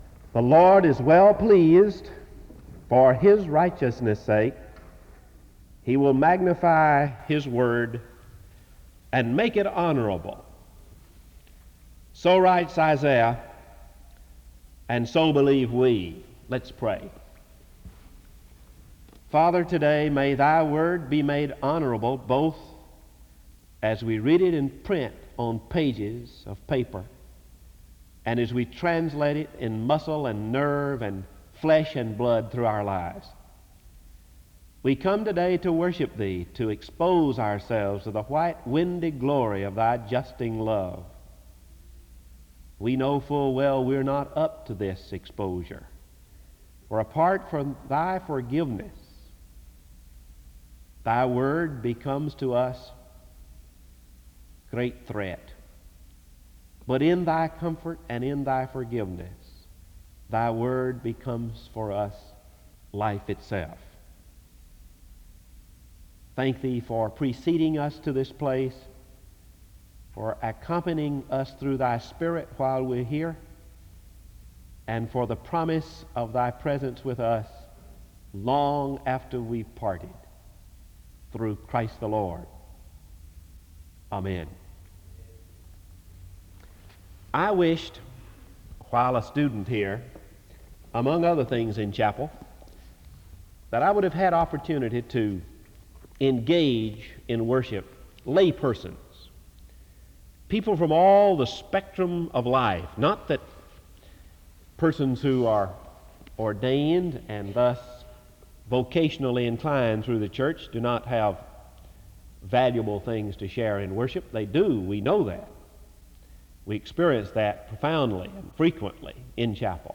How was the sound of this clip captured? The service starts with a scripture reading and prayer from 0:00-1:25. An introduction is given to the speaker from 1:27-5:24.